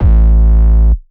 TM88 RockStar808.wav